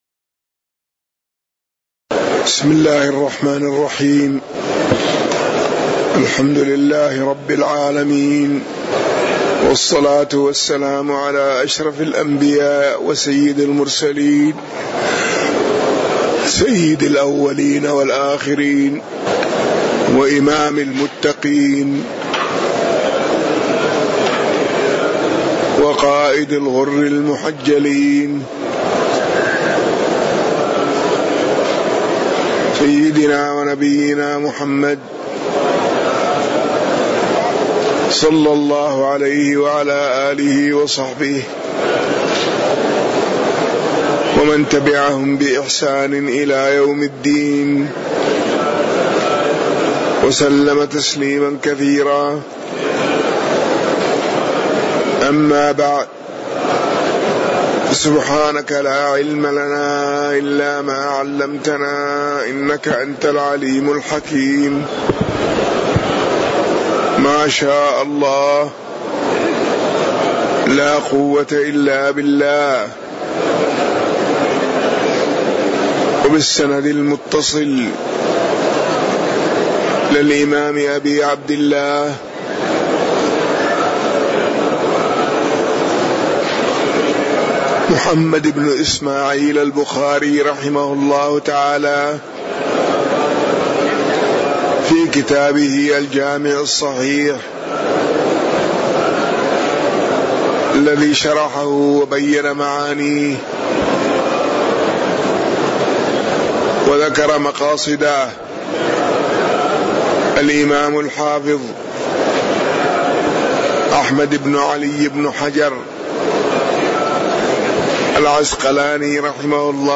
تاريخ النشر ٨ رجب ١٤٣٩ هـ المكان: المسجد النبوي الشيخ